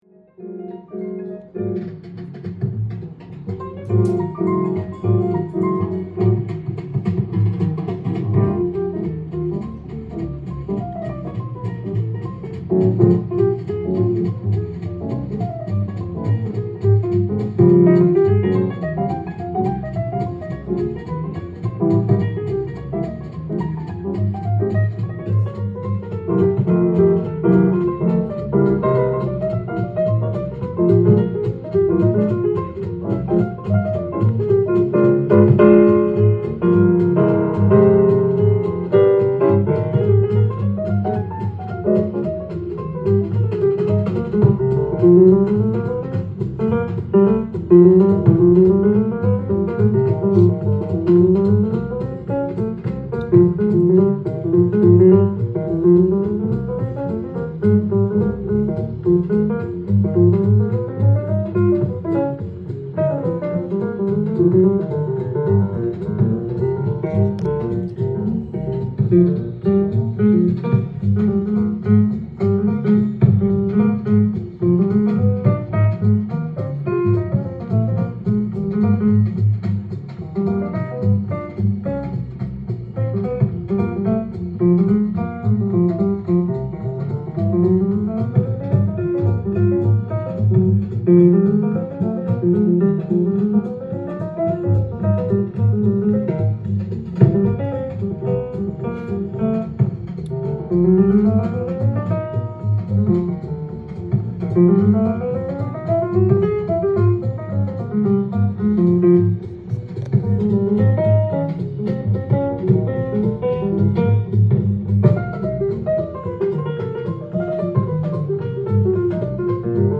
ジャンル：JAZZ-ALL
店頭で録音した音源の為、多少の外部音や音質の悪さはございますが、サンプルとしてご視聴ください。
彼のトリオが1954年12月17日、NYのTOWN HALLで行ったコンサートを実録した一枚。
スウィンギーで小気味良いピアノ・トリオ名演